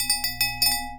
chime_bell_07.wav